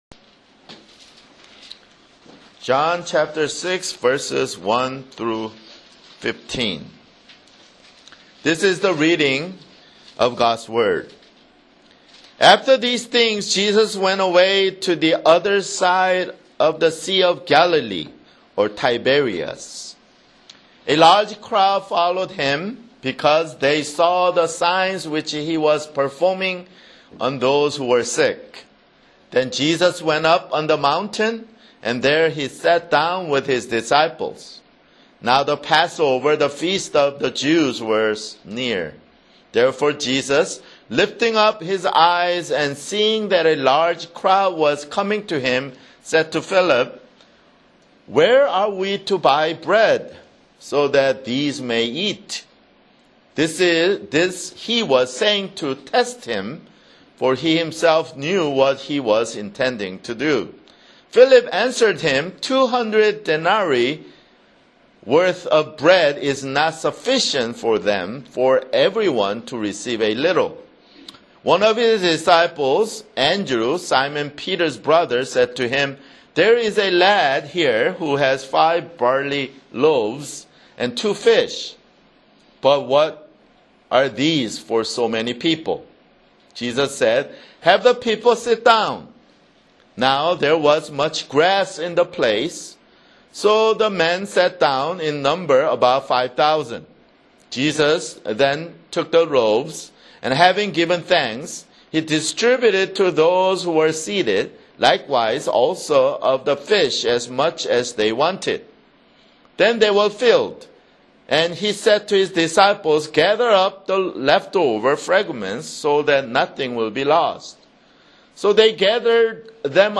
[Sermon] John 6 (1)